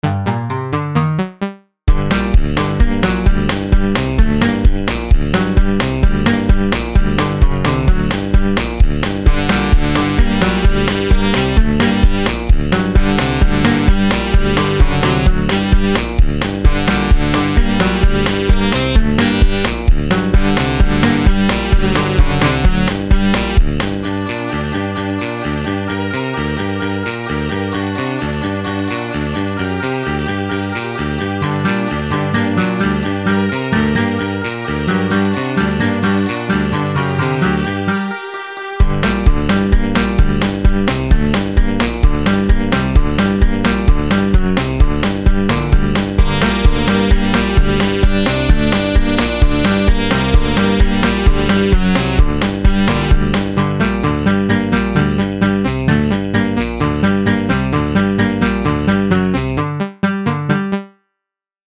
I made this high-energy chase song with really loud bass in the drums so it sounds kinda funny.
silly_chase_0.mp3